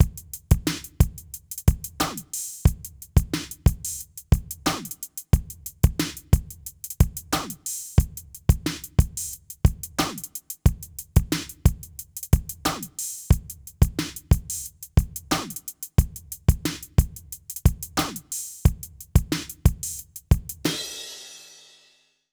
British REGGAE Loop 091BPM.wav